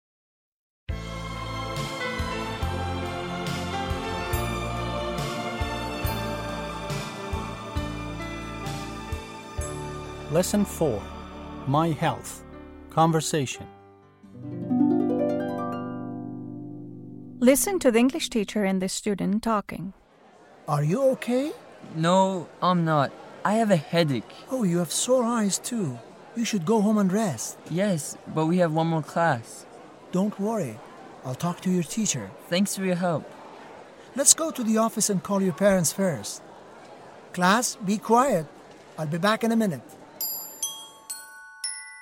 8-L4-Conversation.mp3